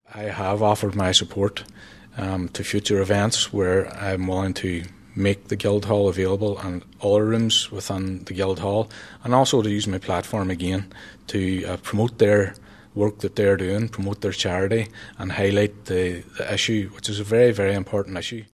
Ruairí McHugh says he’ll continue to work with groups on the ground: